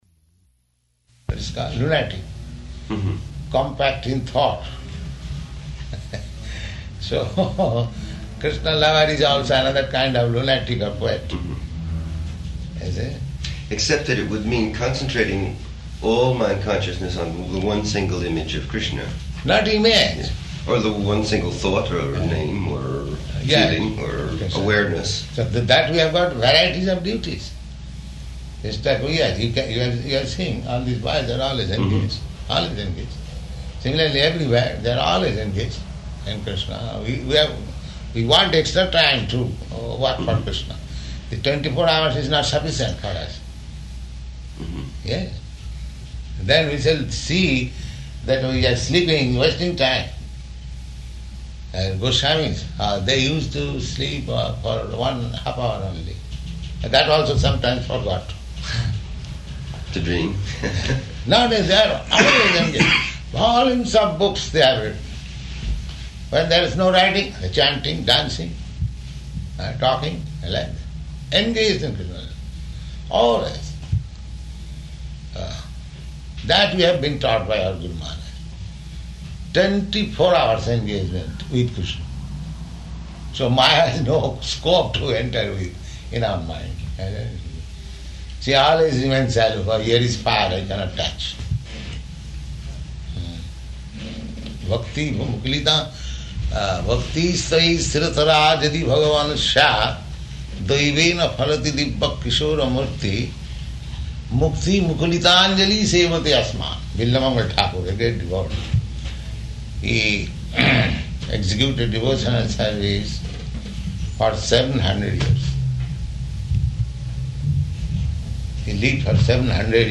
Room Conversation with Allen Ginsberg
Room Conversation with Allen Ginsberg --:-- --:-- Type: Conversation Dated: May 13th 1969 Location: Colombus Audio file: 690513R1-COLUMBUS.mp3 Prabhupāda: What is called, lunatic, compact in thought.